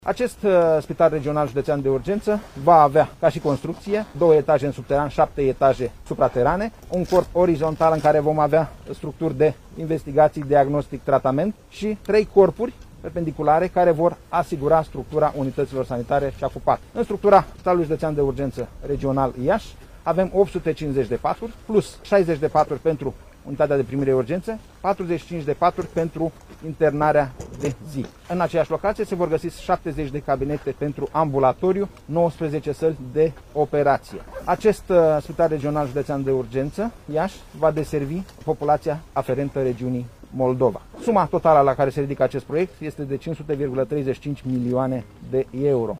Ministrul Nelu Tătaru a precizat şi anumite date tehnice: